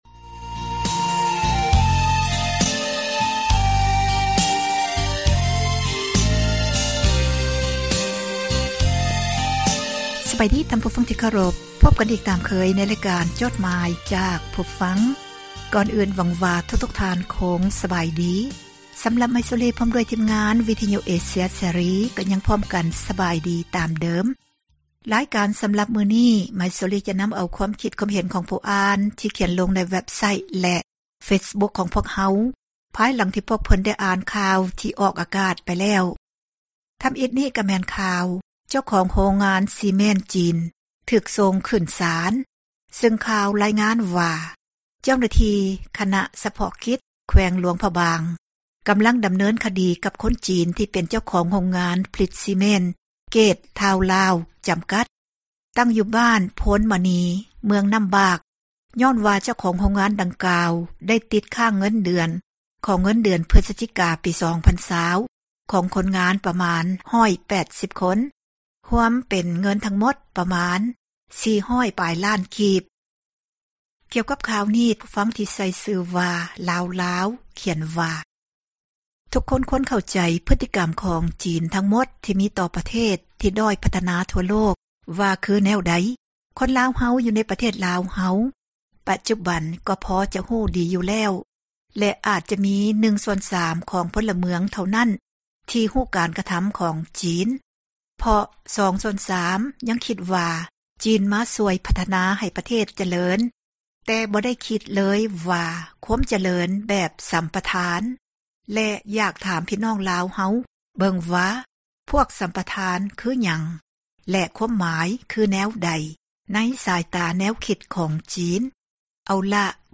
ອ່ານຈົດໝາຍ, ຄວາມຄຶດຄວາມເຫັນ ຂອງທ່ານ ສູ່ກັນຟັງ